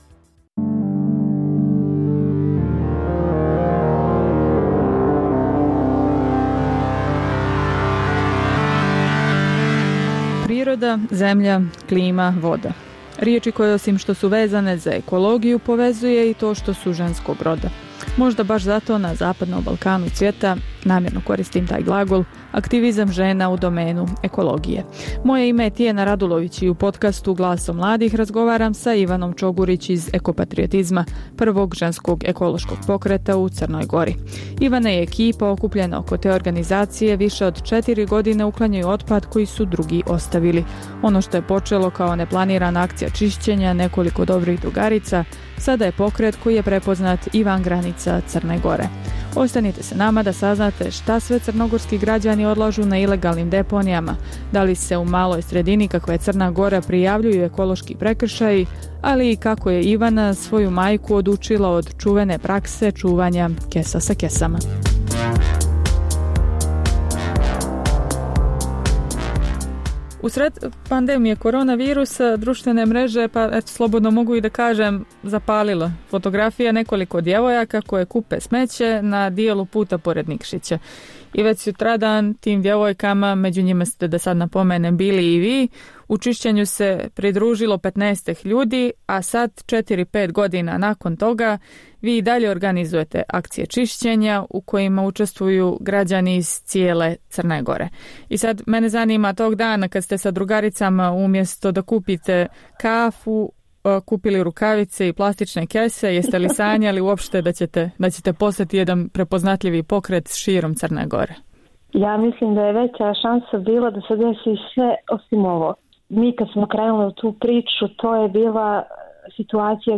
Najpopularnije teme i reportaže iz programa Radija Slobodna Evropa.